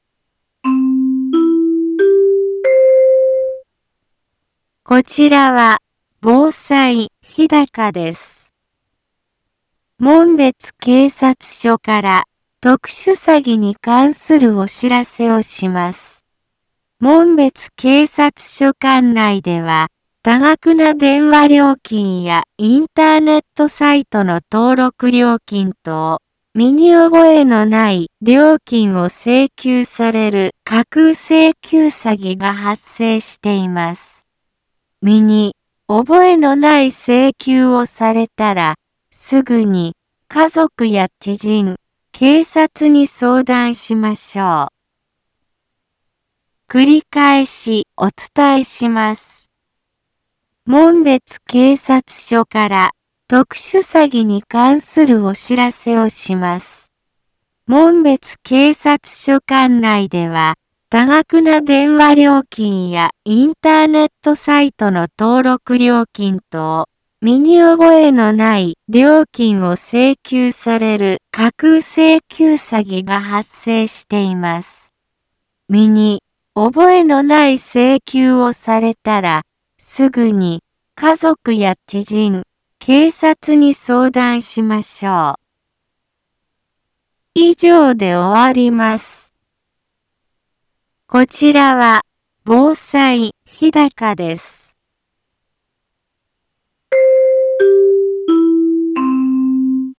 一般放送情報
Back Home 一般放送情報 音声放送 再生 一般放送情報 登録日時：2020-05-11 15:03:31 タイトル：特殊詐欺被害防止について インフォメーション：こちらは、防災日高です。 門別警察署から特殊詐欺に関するお知らせをします。